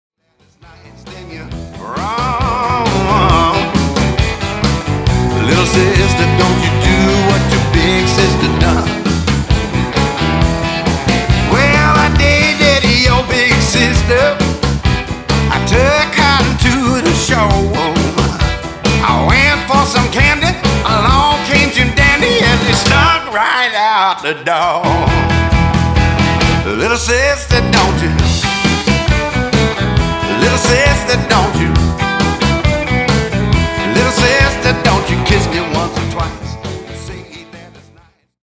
Vocal & Digital Piano
Baritone Guitar
Recorded at Tony’s Treasures Studio, Cadiz, Ohio.